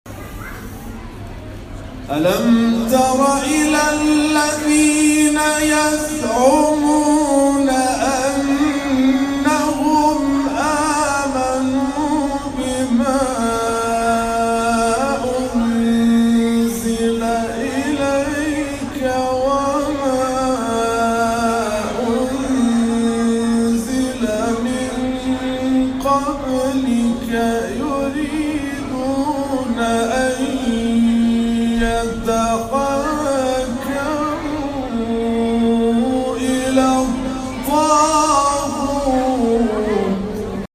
گروه جلسات و محافل: کرسی تلاوت رضوی همزمان با ایام دهه کرامت و ولادت حضرت علی بن موسی الرضا(ع) در مسجد سیدالشهداء شهر اراک برگزار شد.